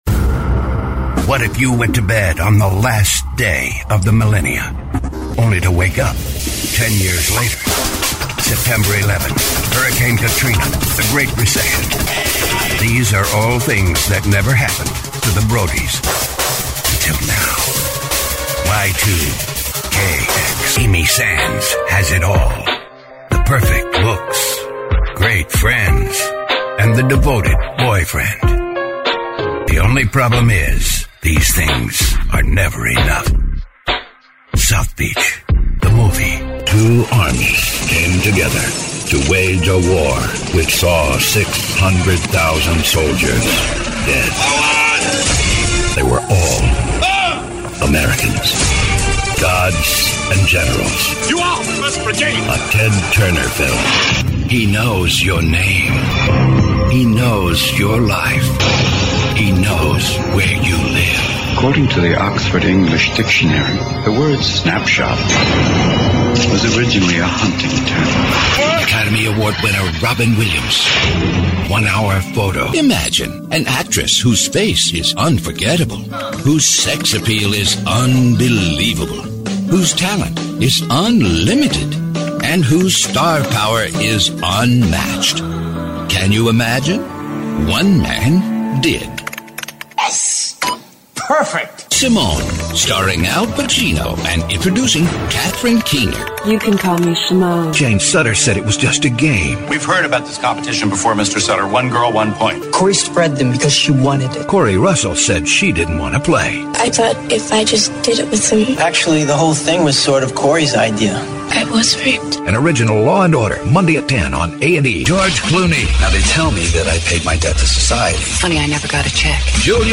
Middle Aged
Quiet in-house audio booth. Classic 1981 Neumann U-87.